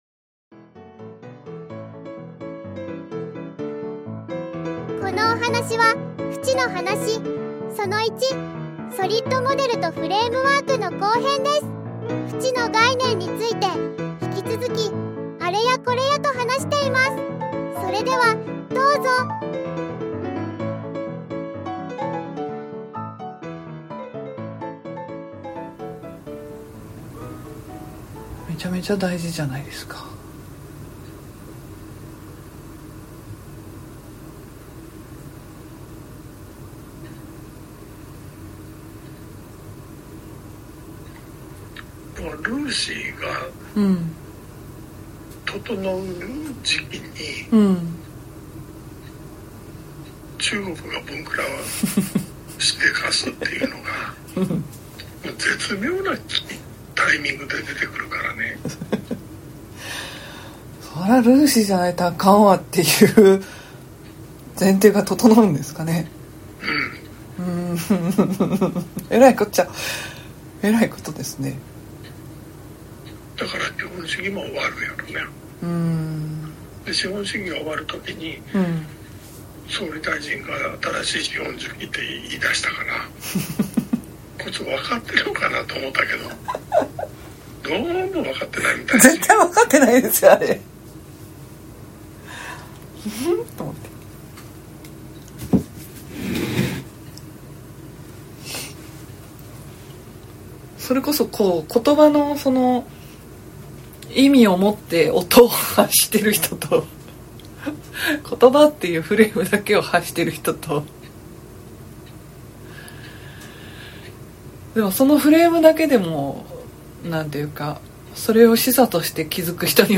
12月以降は、「よもやも」の 有料視聴枠に移動します ので、今のうちにどうぞ♪ （座談会参加の方でパスワードをもらった方は、この録音になります） トーク内の話題に関して感想やご質問は、 フォーラム または 自立研究会 でいただけるのを、お待ちしてま〜す♪ フチの話_フレームワークとソリッドモデル フチの話_フレームワークとソリッドモデル ※音量調整、注意。